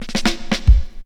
20DR.BREAK.wav